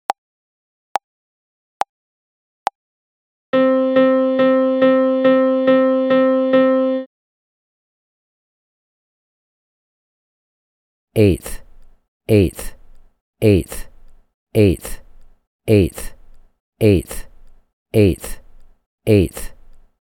• Level 8: Dotted Quarter, Eighth and Quarter Note Triplet Rhythms in 4/4.
Find examples below for each level of the voice answer MP3s:
Rhy_ET_L8_70BPM-1.mp3